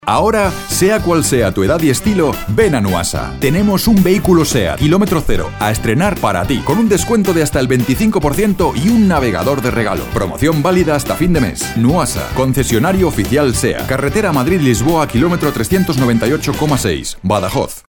Excellent corporate voice. Warm, Kind, Friendly and Expressive.
kastilisch
Sprechprobe: Sonstiges (Muttersprache):